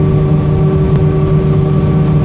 new IAE idle sound